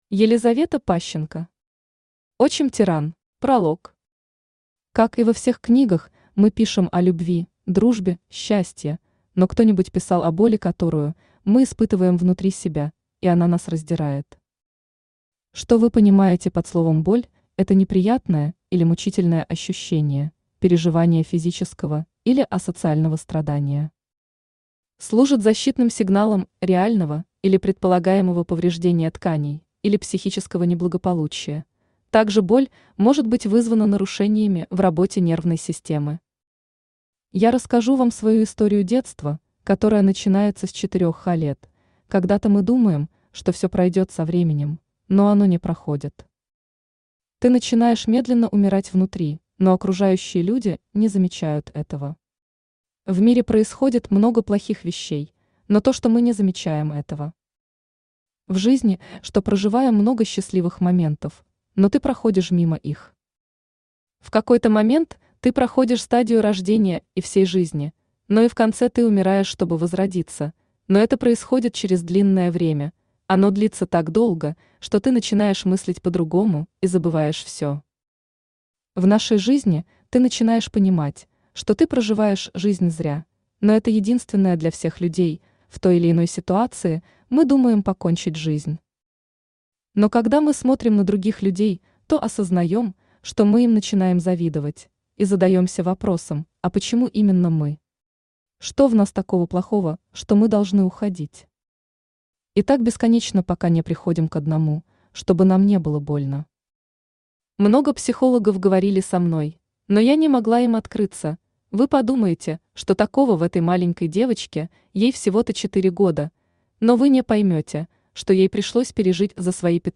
Аудиокнига Отчим-тиран | Библиотека аудиокниг
Aудиокнига Отчим-тиран Автор Елизавета Михайловна Пащенко Читает аудиокнигу Авточтец ЛитРес.